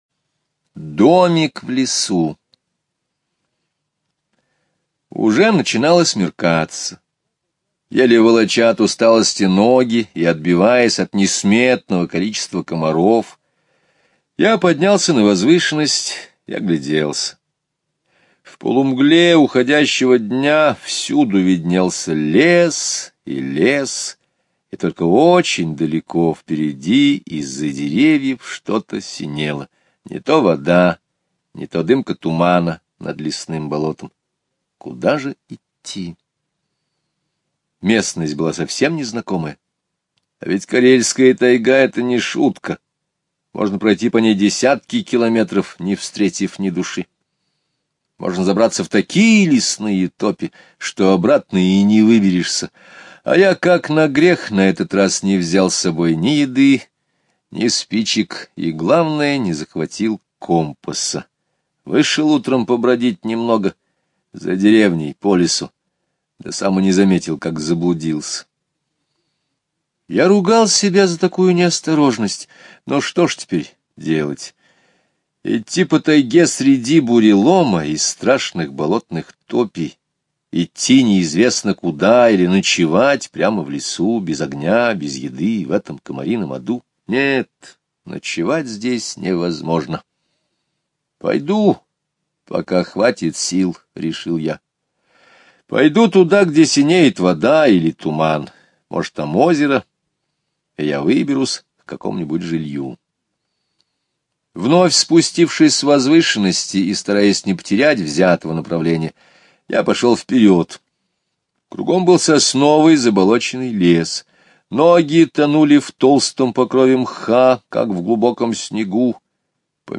Слушайте Домик в лесу - аудио рассказ Скребицкого Г. Рассказ о том, как охотник заблудился в незнакомом лесу без еды, огня и компаса.